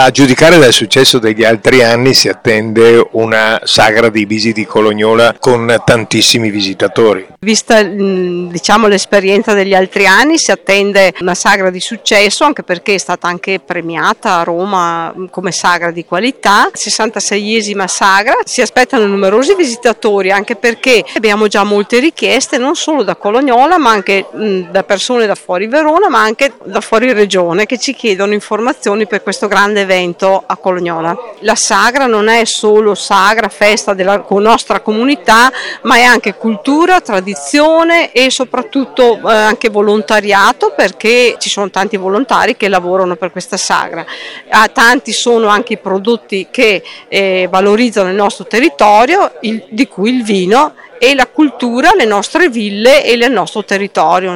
Giovanna Piubello Sindaco di Colognola ai Colli